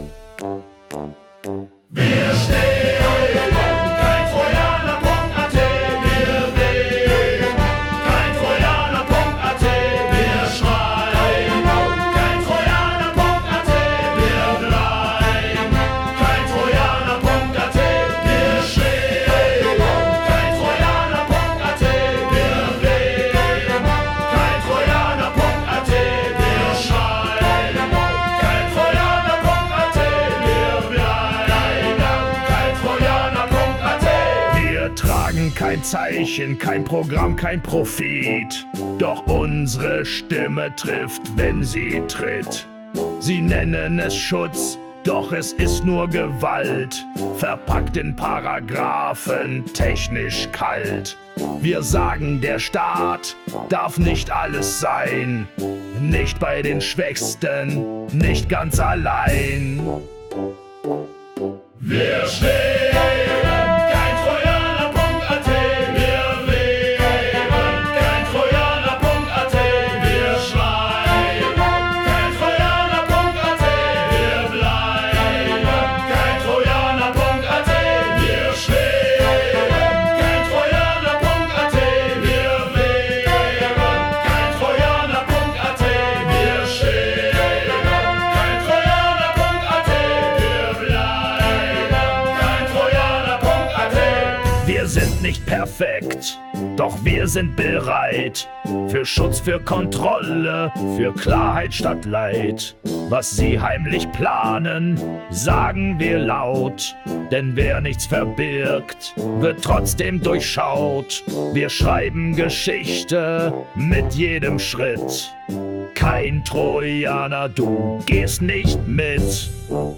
Kein-Trojaner-Marschlied.mp3